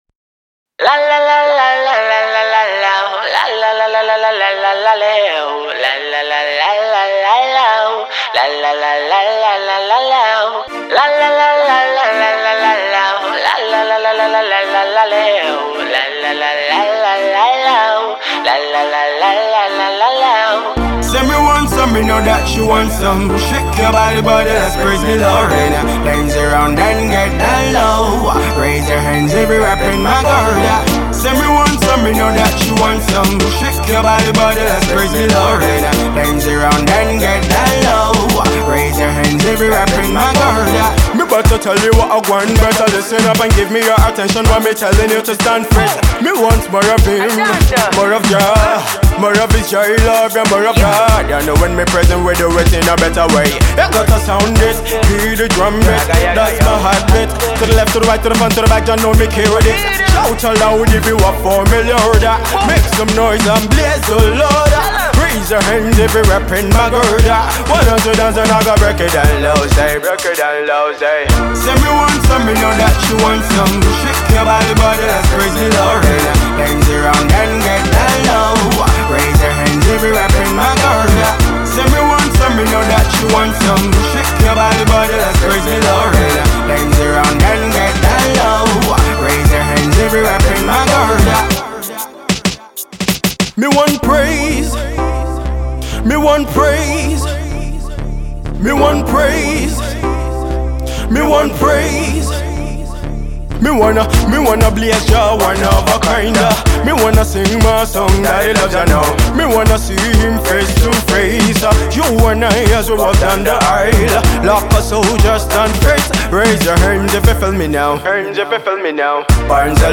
high spirited ragga dance hall tune